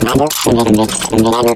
tick_vo_02v2.ogg